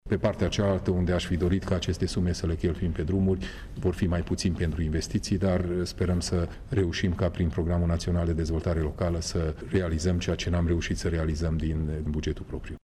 Odată cu alocarea celor 77 de milioane de lei din bugetul județului pentru lucrările de la aeroport, vor rămâne mai puțini bani pentru investițiile necesare reabilitării drumurilor, spune președintele CJ, Peter Ferenc: